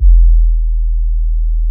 TM88 Short808.wav